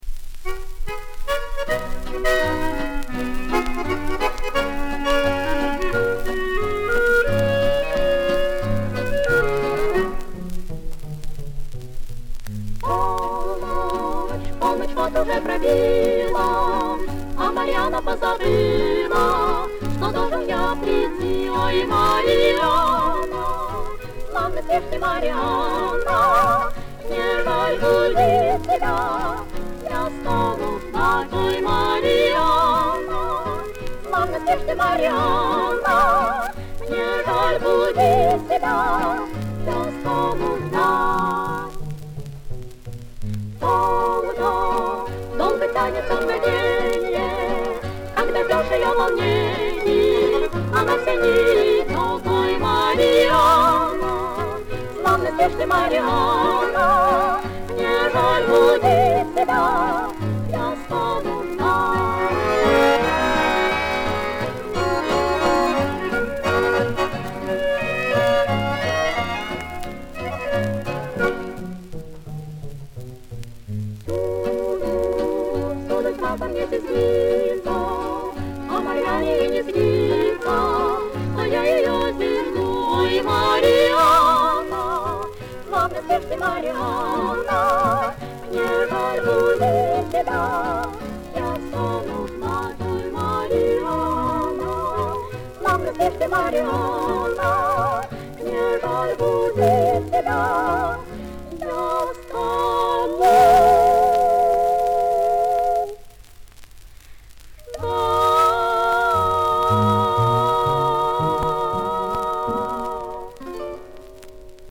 Хорватская песня Слова
Вокальное трио